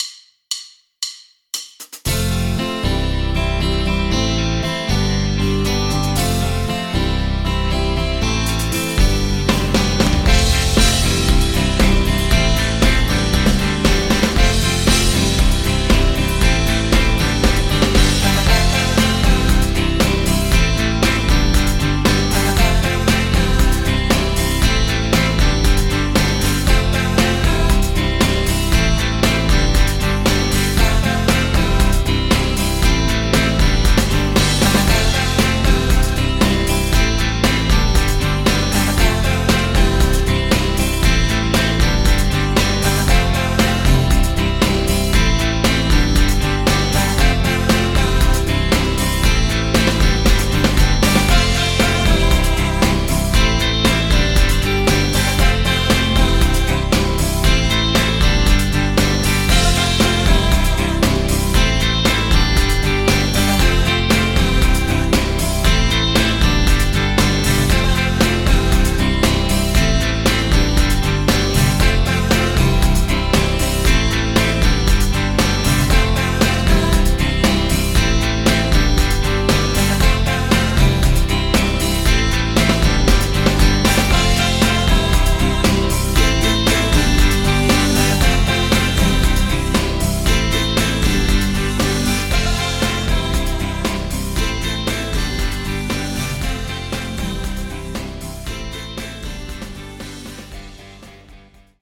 Instrumental, Karaoke